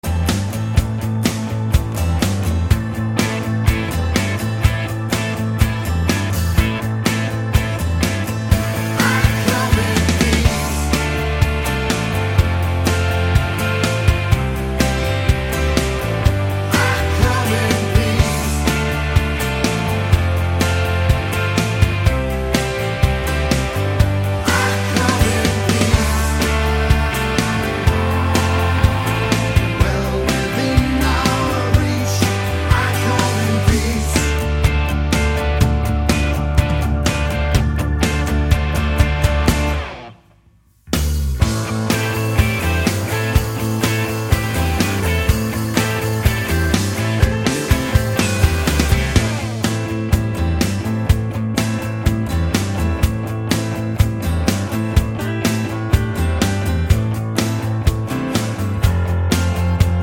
no Backing Vocals Soft Rock 4:17 Buy £1.50